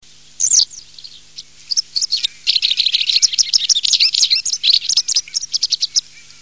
Bergeronnette grise, motacilla alba
bergeronnette.mp3